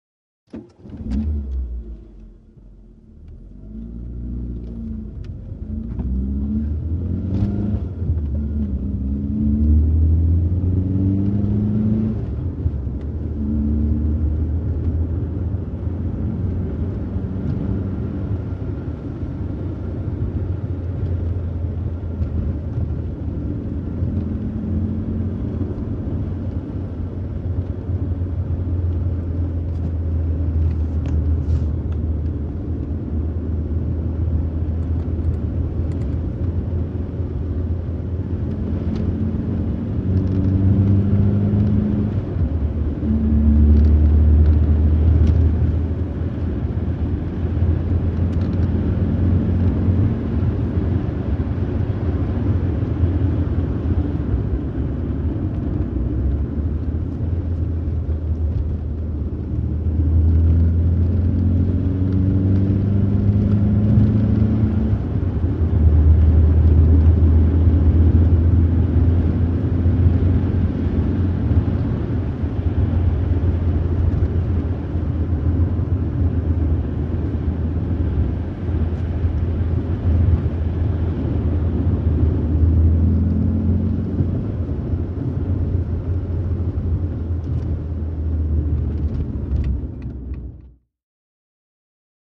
VEHICLES ASTON MONTEGO: INT: Start, constant run.